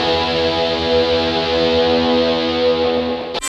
Index of /90_sSampleCDs/Roland L-CD701/GTR_GTR FX/GTR_E.Guitar FX
GTR CHUCK00L.wav